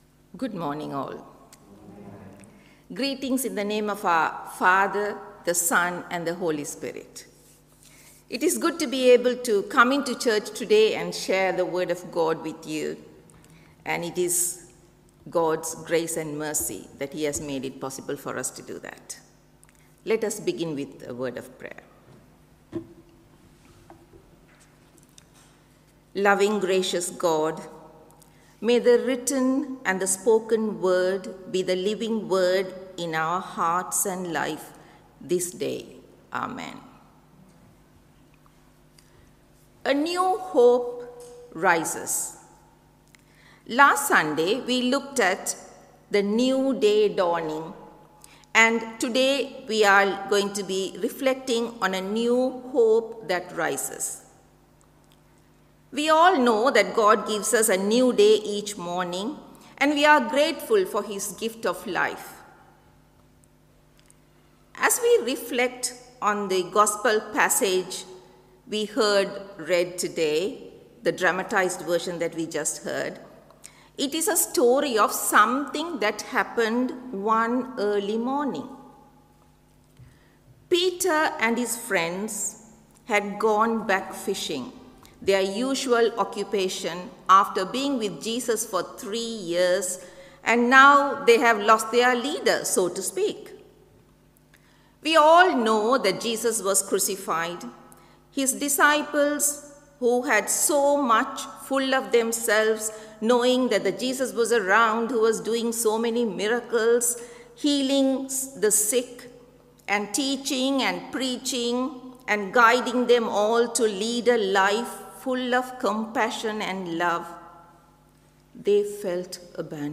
Live Stream Worship